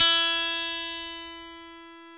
GTR EL-AC104.wav